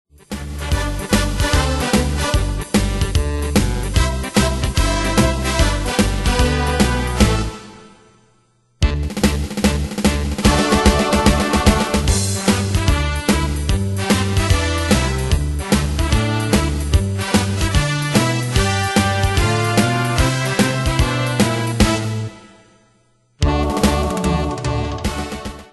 Demos Midi Audio
Style: Retro Année/Year: 1965 Tempo: 148 Durée/Time: 2.51
Danse/Dance: Ska Cat Id.
Pro Backing Tracks